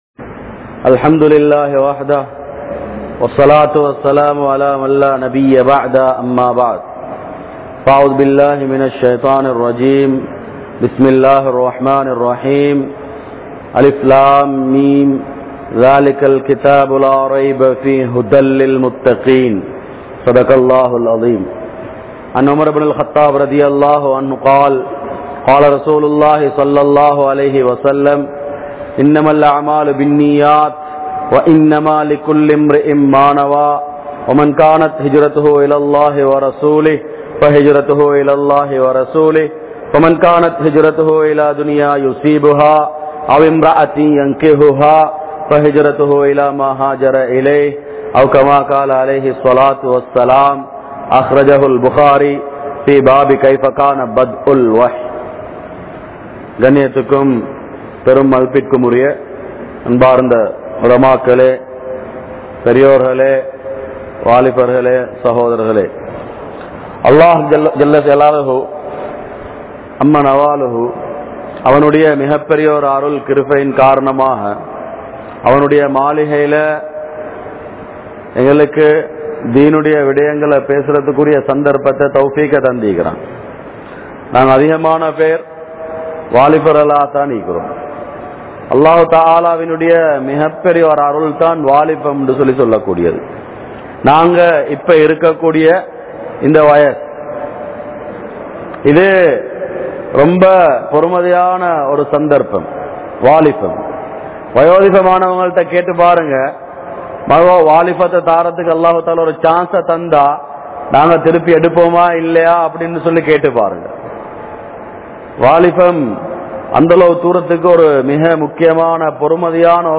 Vaalifarhalai Eamaattrum Ulaham (வாலிபர்களை ஏமாற்றும் உலகம்) | Audio Bayans | All Ceylon Muslim Youth Community | Addalaichenai